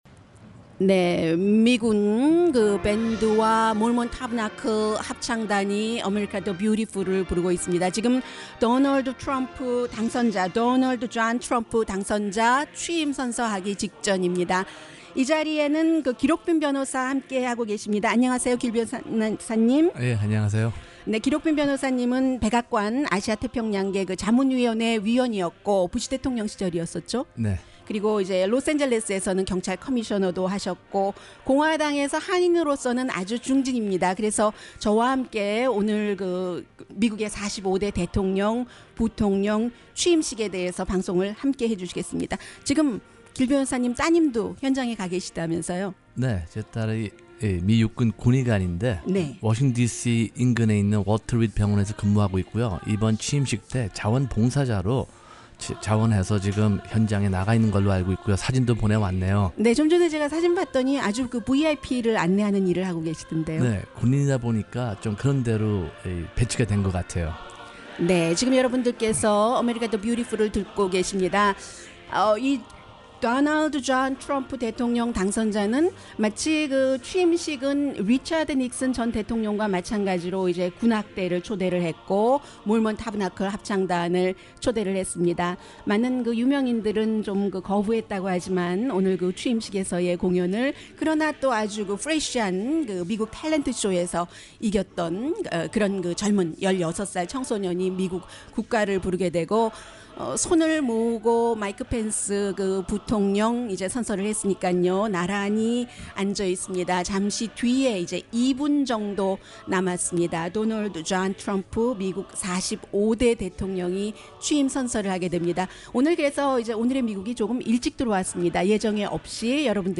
트럼프 대통령의 취임연설문에 대한 해석. 아래(한국어 동시통역 라디오 프로그램)